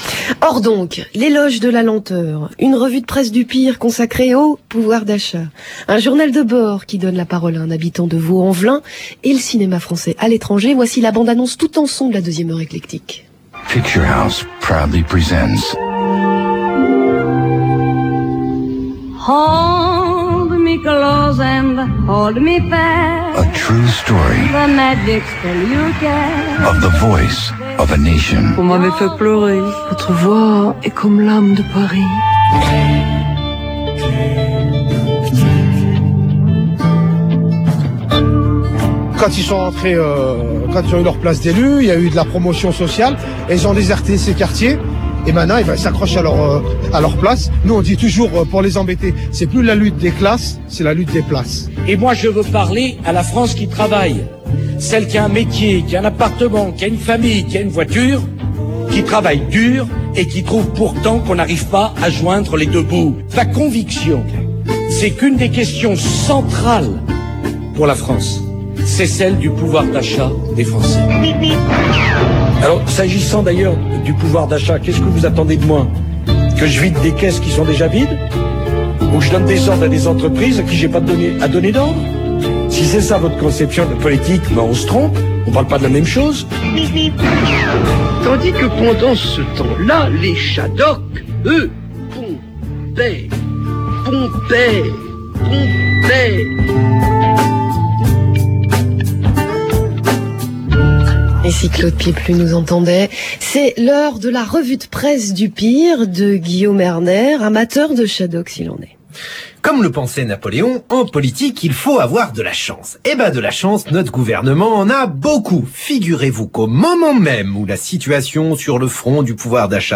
Parce qu’il faut bien rire un peu, cette chronique de Guillaume Erner (Revue de Presse du Pire – éclectik – France Inter – 12/01/2008) :